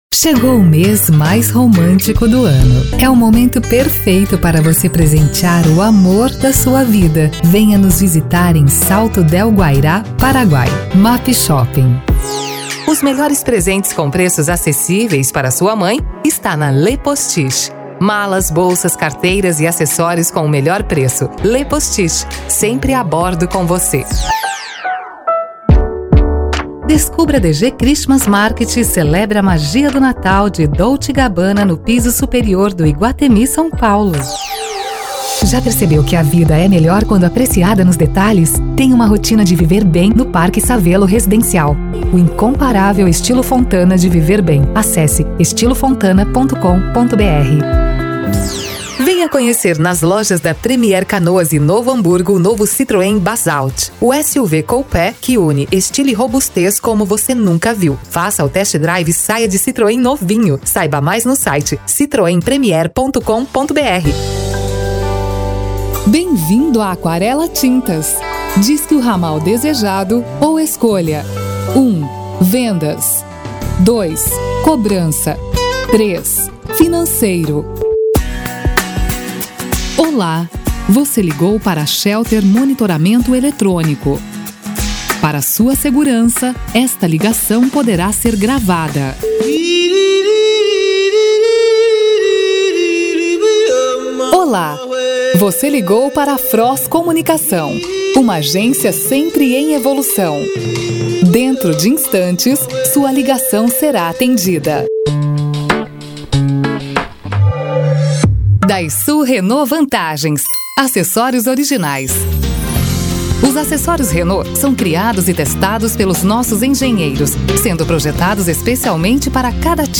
Spot Comercial
Vinhetas
Impacto
Animada
LOCUTORA INCRÍVEL!!!!!!